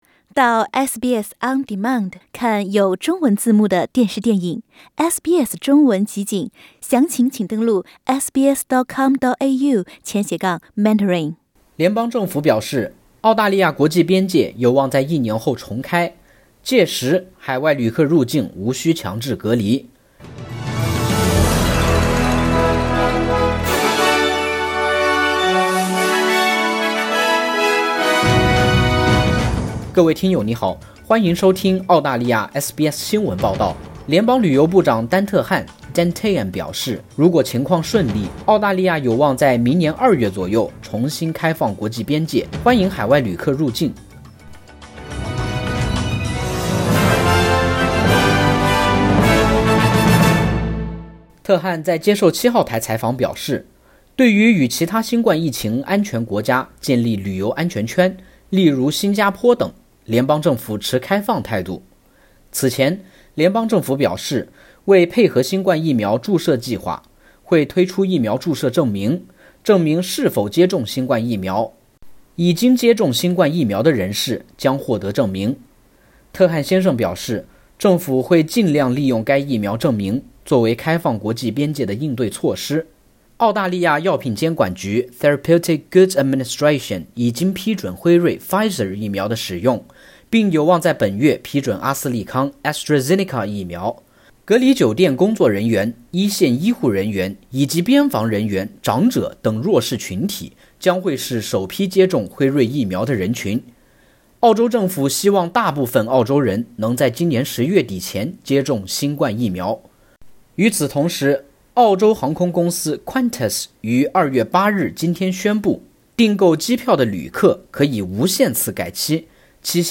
联邦政府表示，澳大利亚国际边界有望在一年后重开，届时海外旅客入境无须强制隔离。（点击上图收听录音报道）